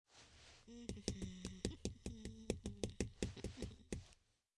avatar_emotion_bored.mp3